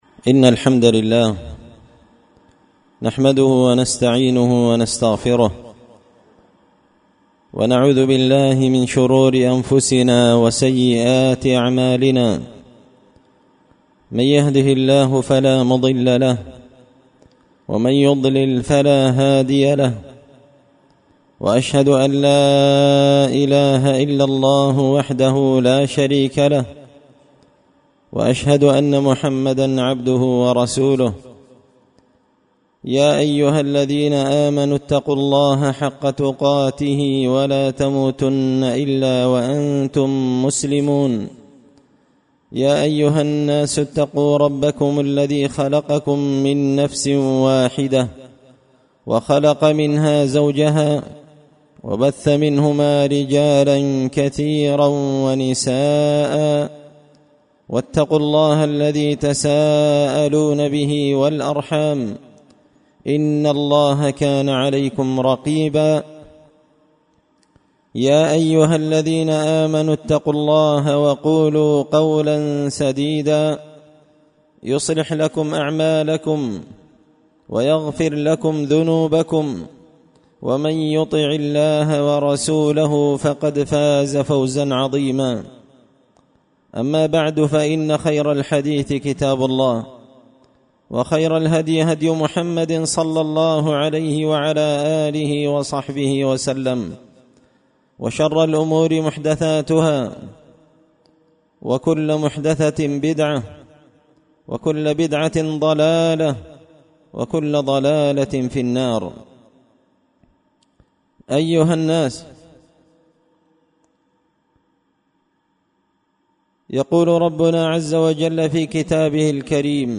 خطبة جمعة بعنوان – صور لما كان عليه النبي من الأمر بالمعروف والنهي عن المنكر
دار الحديث بمسجد الفرقان ـ قشن ـ المهرة ـ اليمن